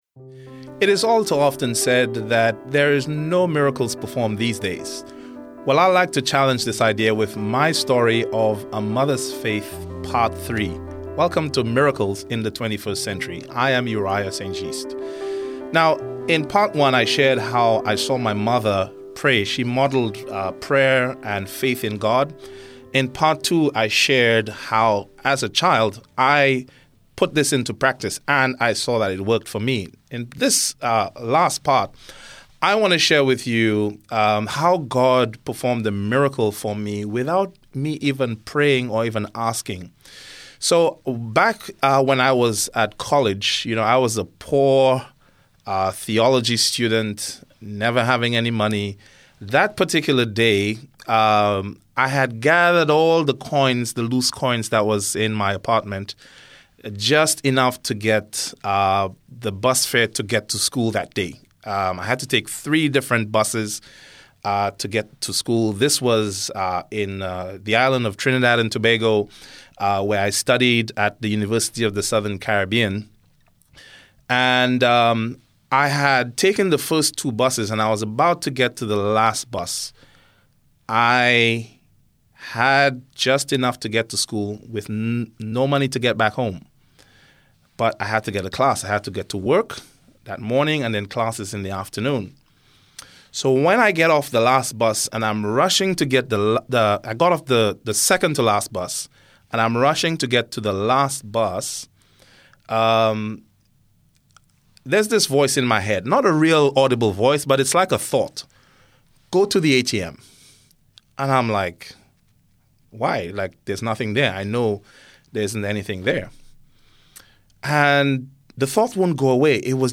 Yes, listen to people share real life miracle stories, not only from recent times but also amazing stories from their past. These miracles from heaven are truly inspirational!